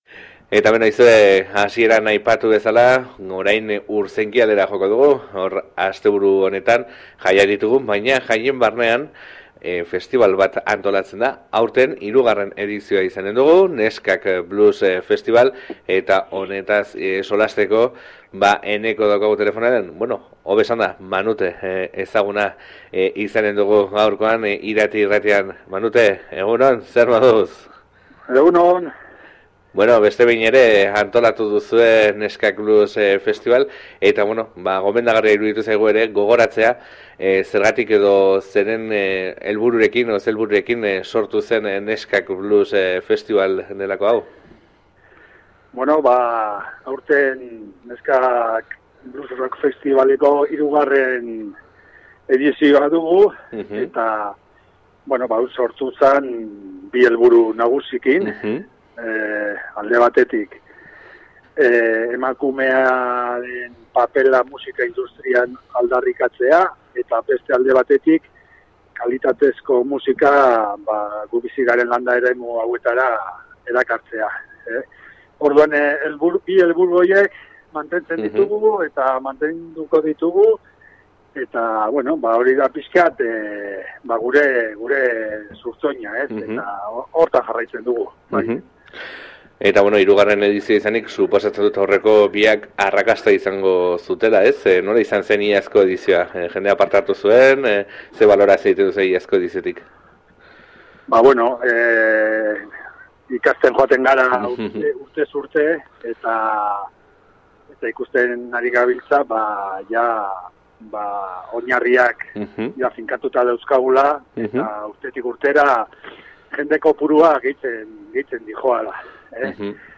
Klikatu hemen elkarrizketa jaisteko Partekatu Click to share on Facebook (Opens in new window) Click to share on Twitter (Opens in new window) Click to email a link to a friend (Opens in new window) Related